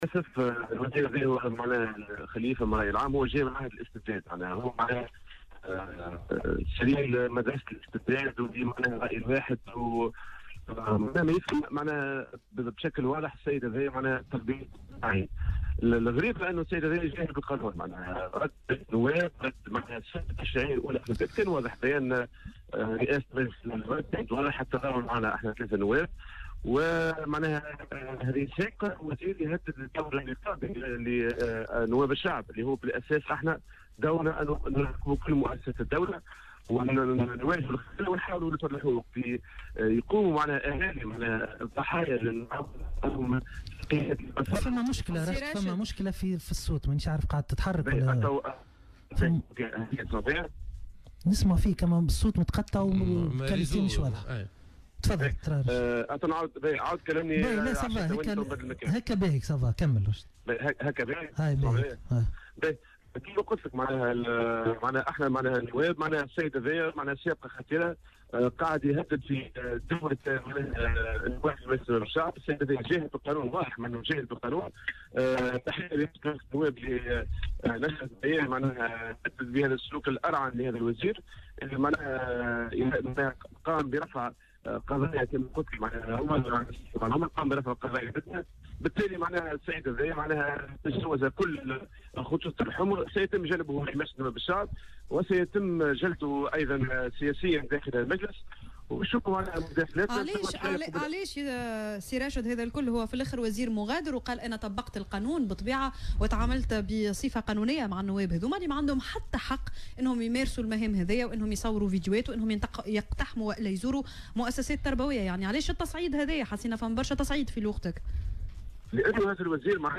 وأضاف في مداخلة له اليوم على "الجوهرة أف أم" أن تهديد الوزير الذي وصفه "بسليل مدرسة الاستبداد"، لنواب الشعب سابقة خطيرة، وفق تعبيره.